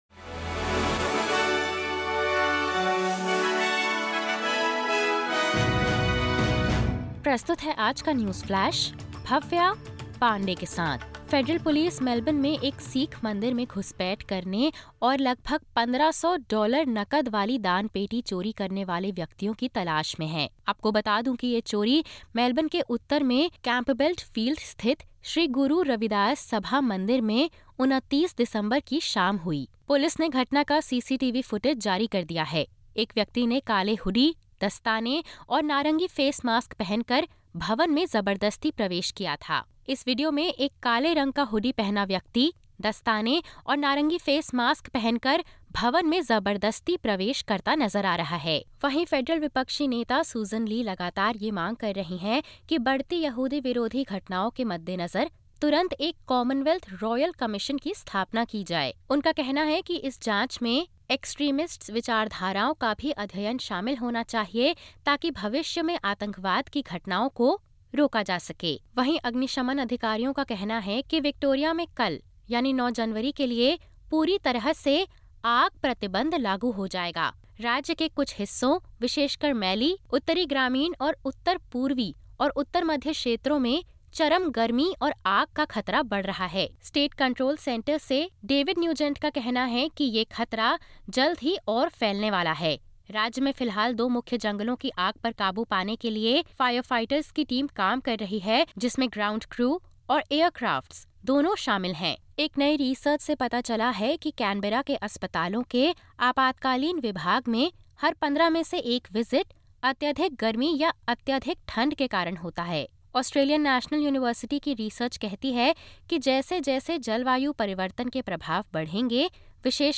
Top News: विक्टोरिया में बढ़ती गर्मी के बीच पूर्ण फायर बैन की संभावना